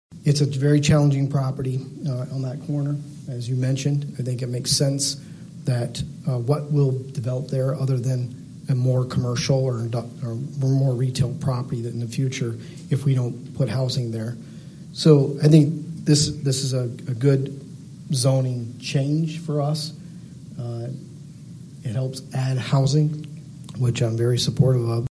Fifth Ward Councilman Scott Corbin expressed the sentiment of his colleagues in unanimously approving the change.